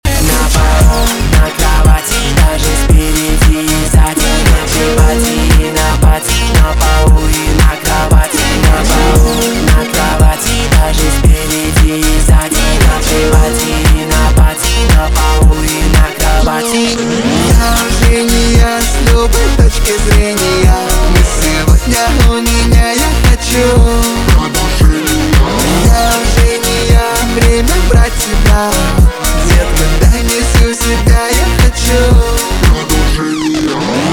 поп
басы , танцевальные